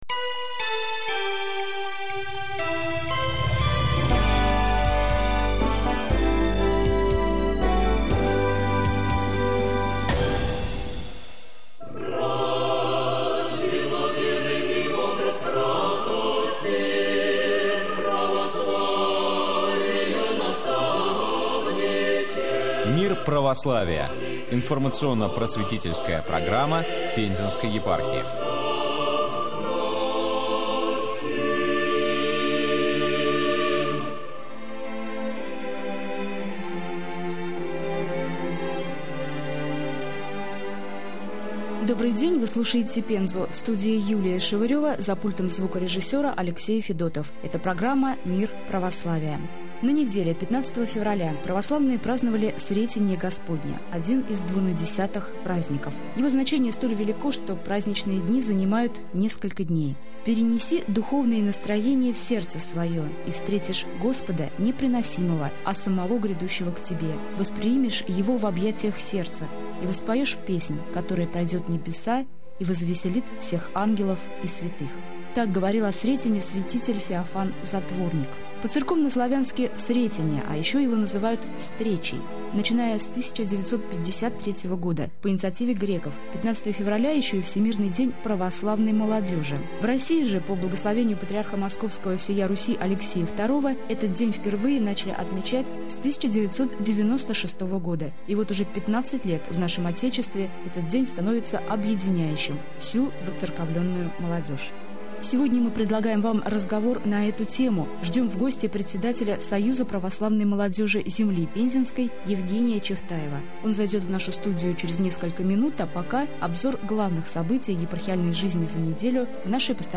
xn----7sbbracknn1actjpi5e2ih.xn--p1ai/wp-content/uploads/2015/09/mp_19.02.11.mp3 Гость